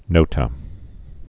(nōtə)